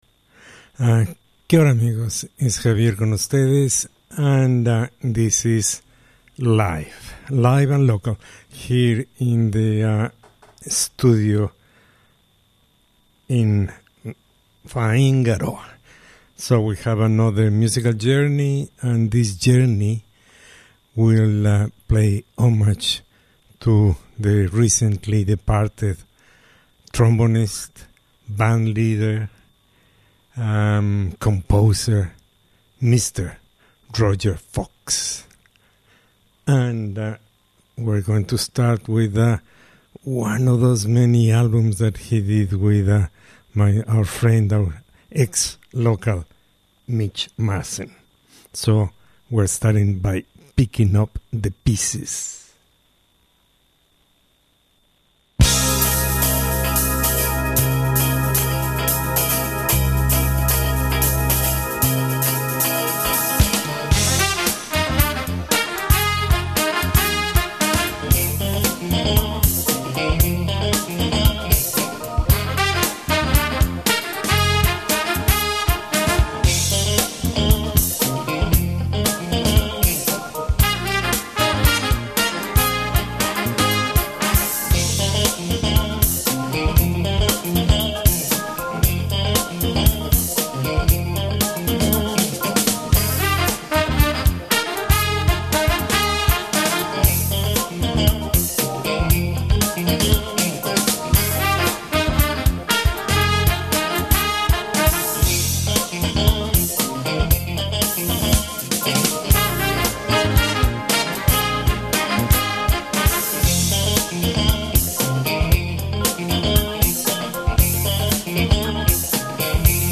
New Zealand / Aotearoa Big Band Jazz